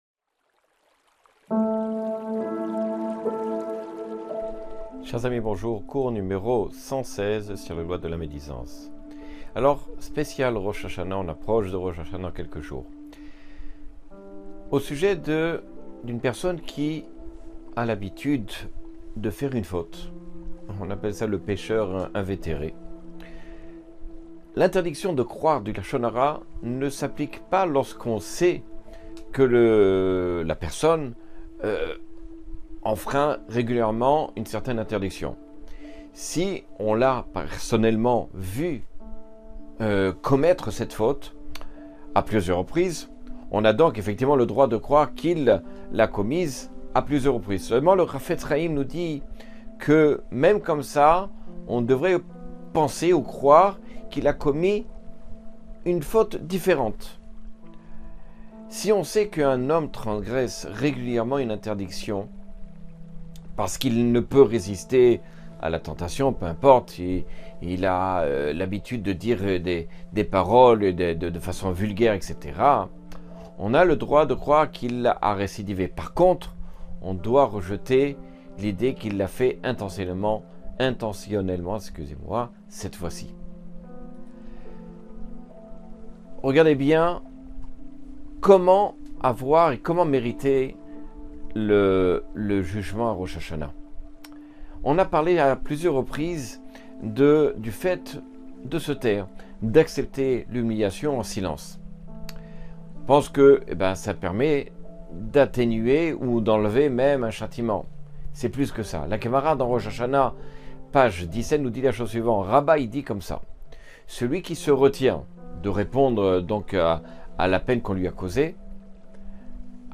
Cours 116 sur les lois du lashon hara.